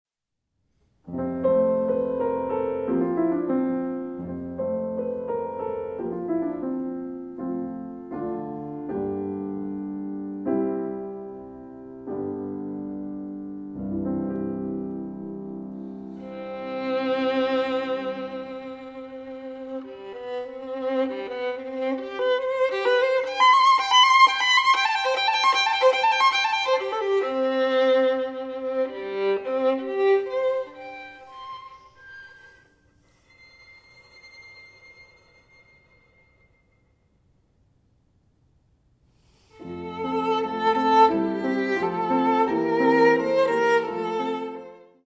for violin and piano
violin
piano